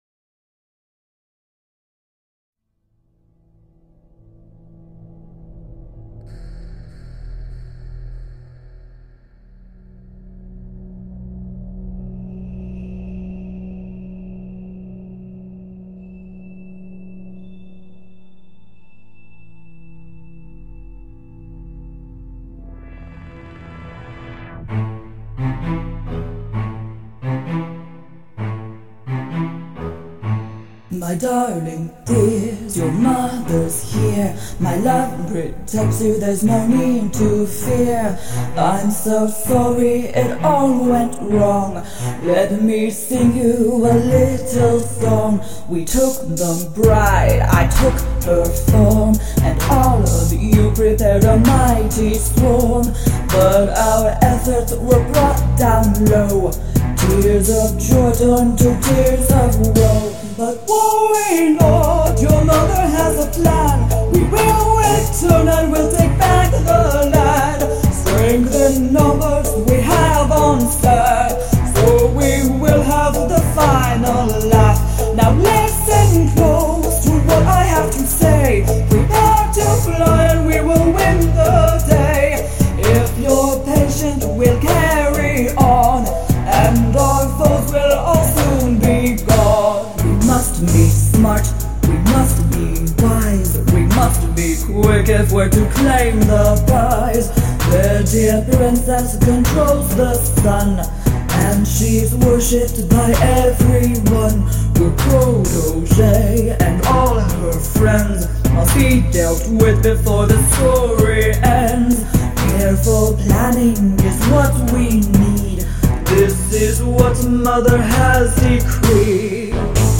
Have some hip-hopera (as I call it).
And if people are picking up a bit of the Habanera from Carmen that was intentional.
+guitars